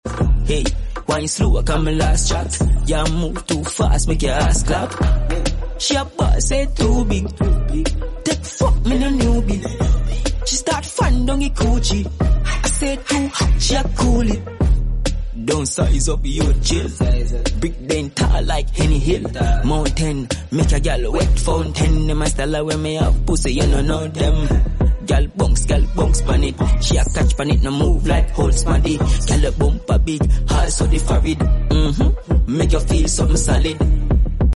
Hey! sound effects free download